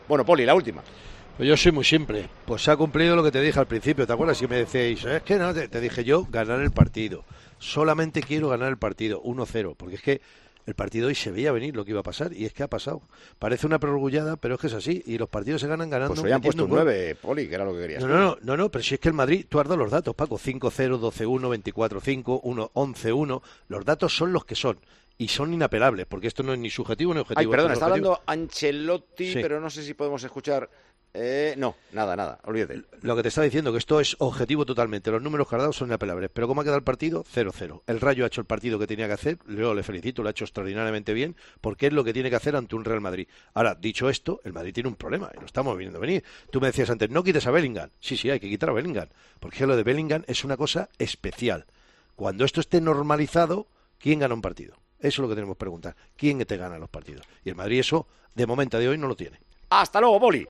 Poli Rincón, comentarista de los partidos del Real Madrid en Tiempo de Juego, se hizo una pregunta en un tono bastante preocupante al término del partido de Liga contra el Rayo.
Poli Rincón reflexiona tras el Real Madrid - Rayo Vallecano, en Tiempo de Juego